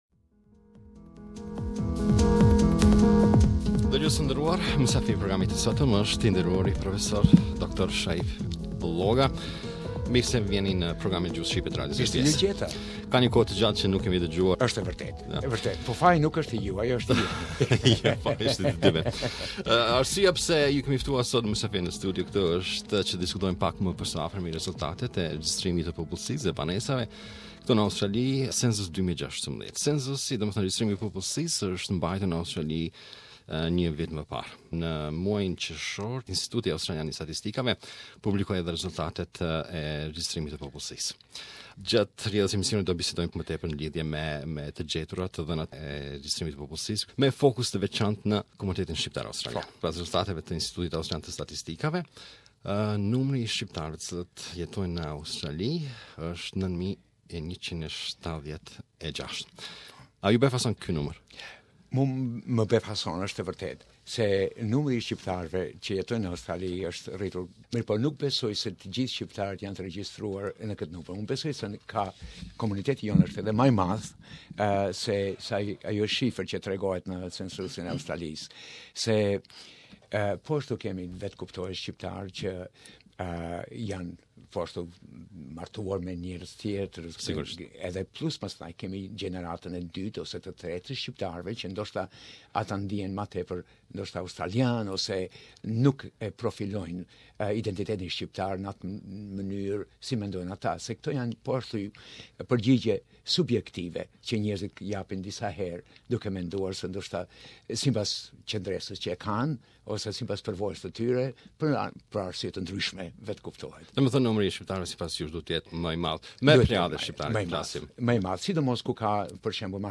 Interview Part one: Share